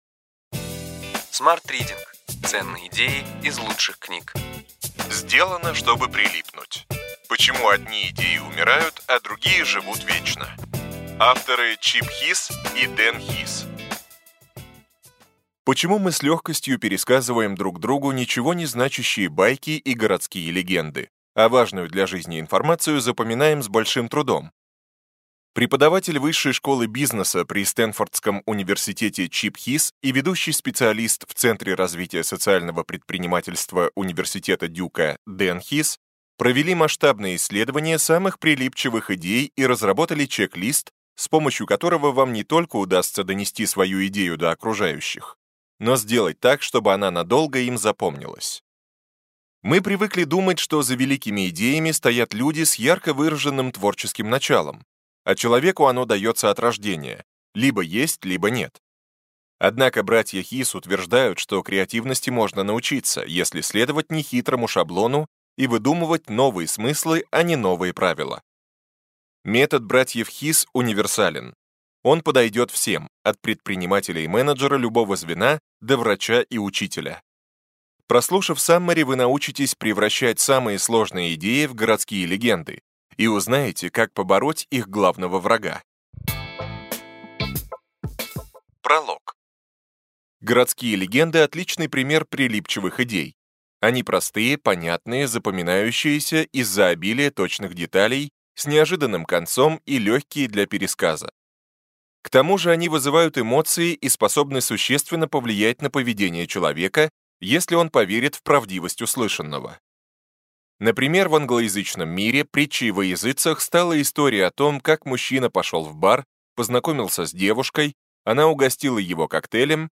Аудиокнига Ключевые идеи книги: Сделано, чтобы прилипнуть. Почему одни идеи умирают, а другие живут вечно.